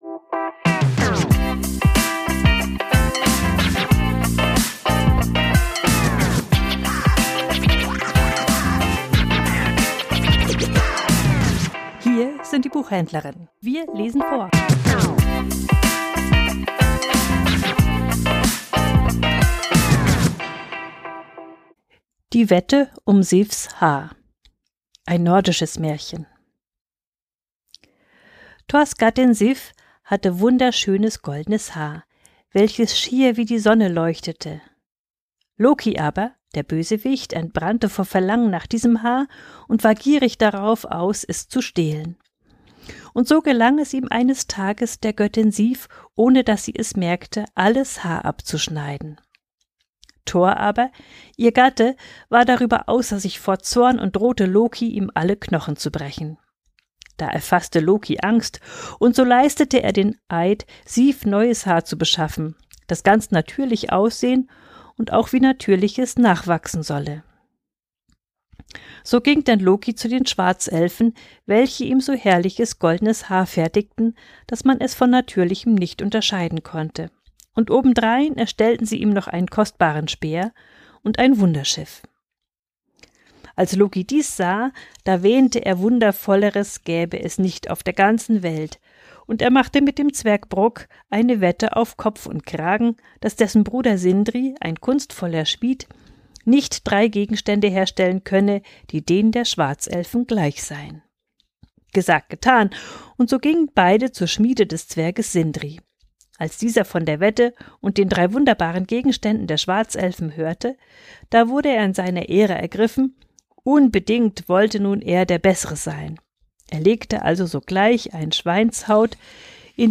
Vorgelesen: Die Wette um Sifs Haar